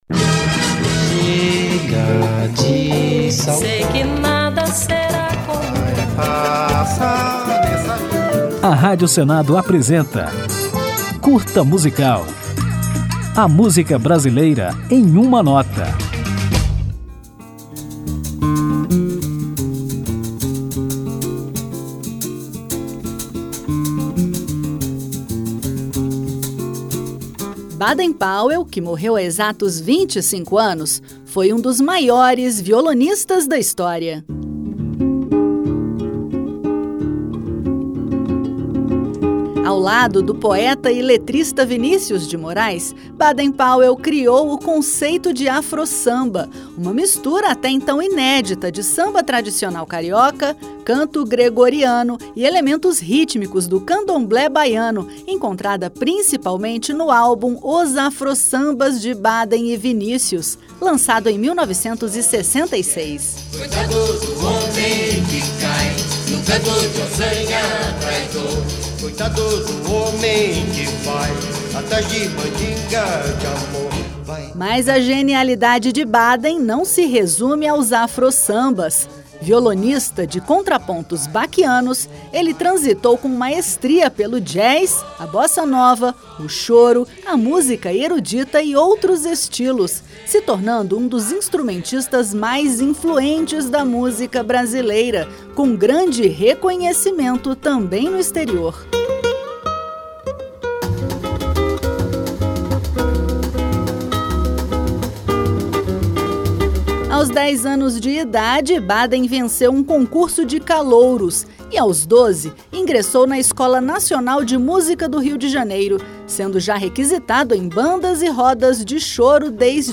Ao final da homenagem, ouviremos Baden Powell na música Berimbau, considerada o primeiro Afro-Samba.